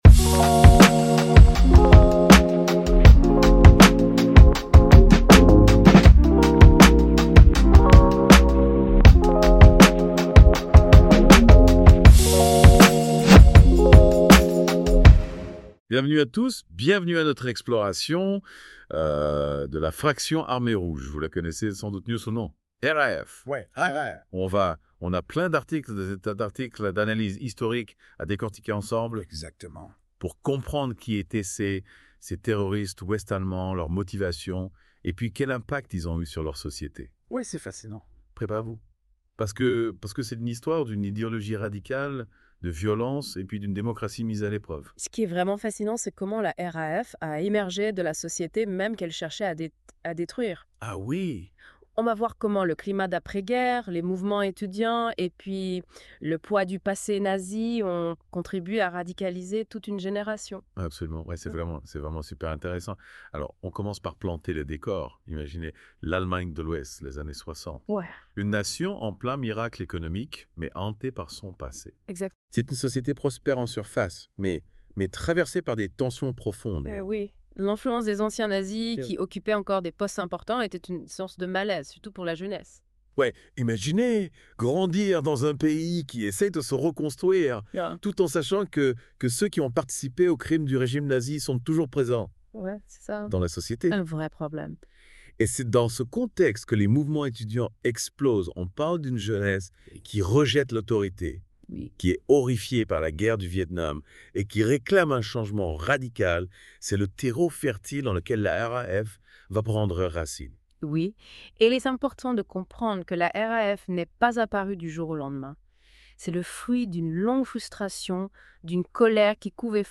Notebook LM Eigennamen nicht stets korrekt ausgesprochen